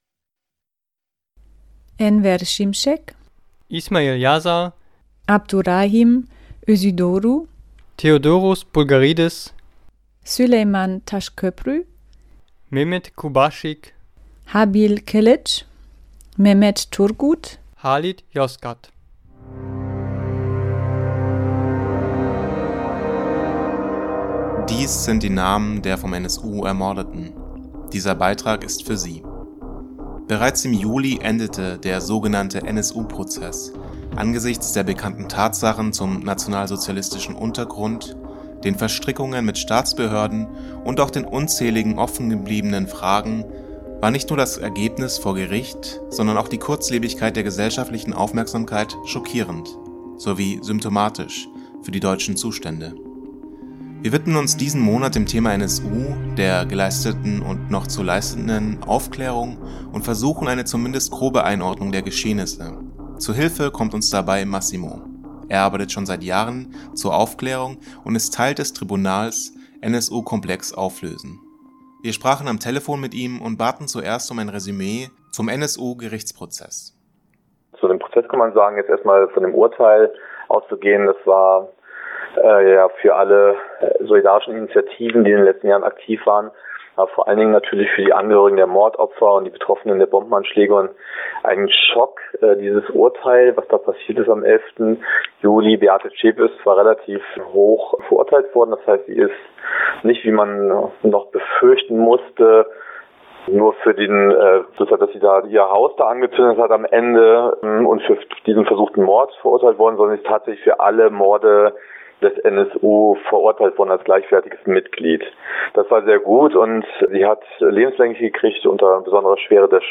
Audio NSU Beitrag mit Interview Download (28,94 MB) NSU_Beitrag_A-Radio_mp3.mp3